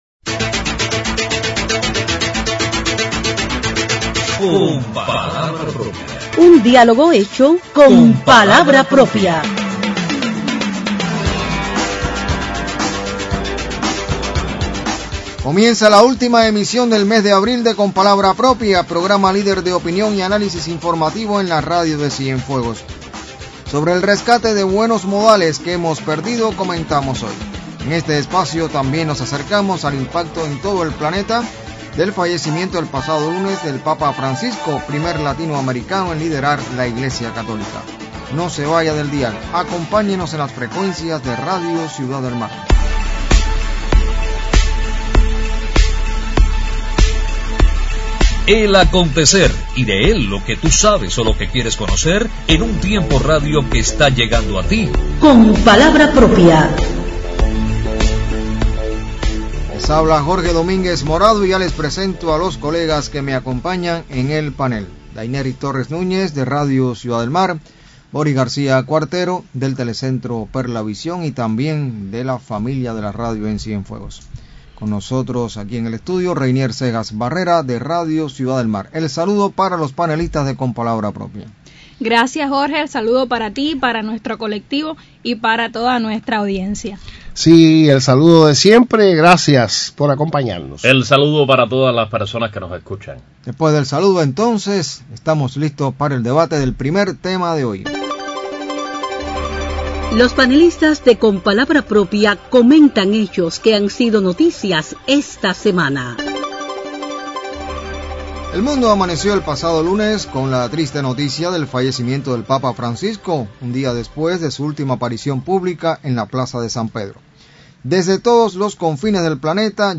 ¿Se han perdido los buenos modales? Sobre este tema comentan en el panel de Con palabra propia en la emisión del sábado 26 de abril.